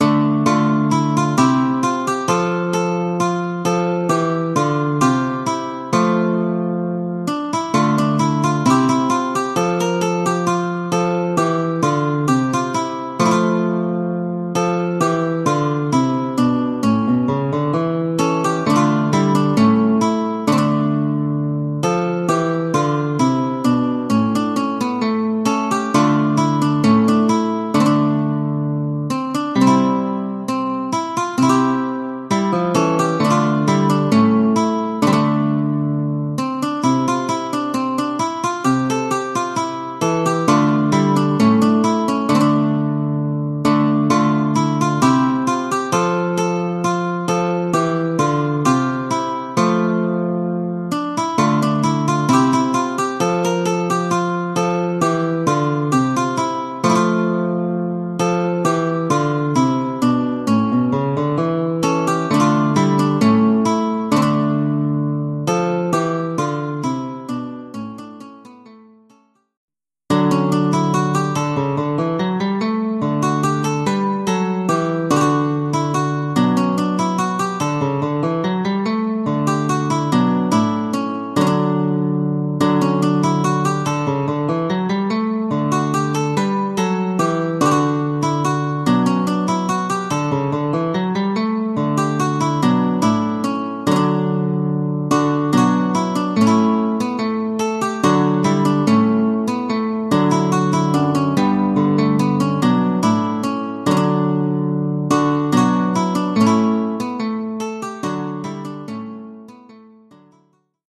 1 titre, deux oeuvres pour guitare : partie de guitare
Deux oeuvres pour guitare solo.